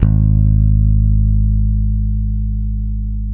-JP ROCK.G#2.wav